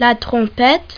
trumpet.mp3